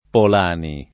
[ pol # ni ]